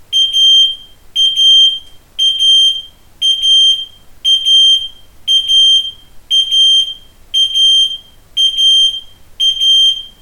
• Funkrauchmelder
• Lautstärke im Test: 92,7 dBA
pyrexx-px-1c-funkrauchmelder-alarm.mp3